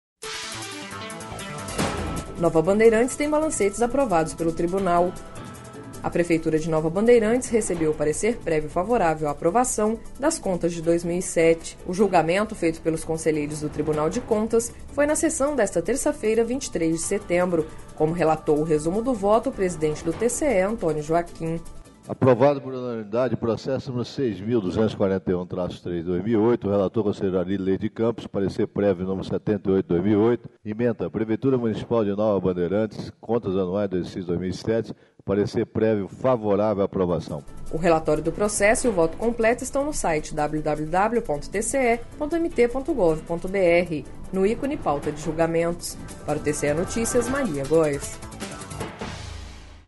Sonora: Antonio Joaquim - conselheiro presidente do TCE-MT